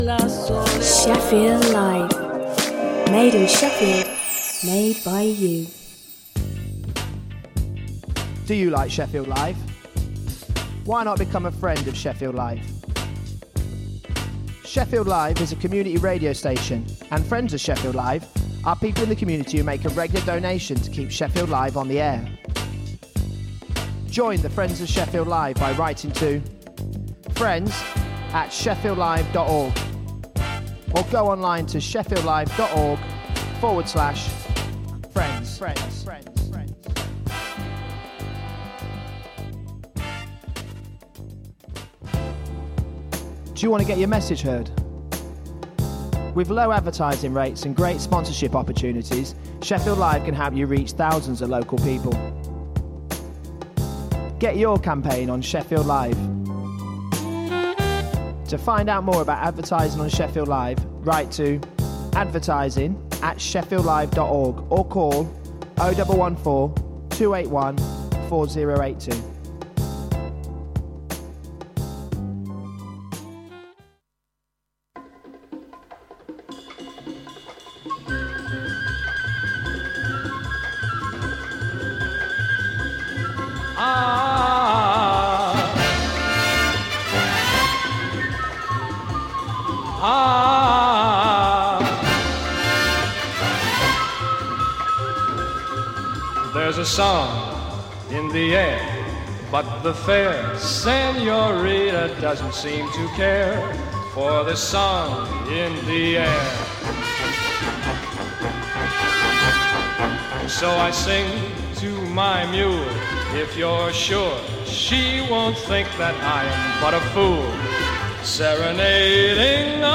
Film and theatre reviews plus swing classics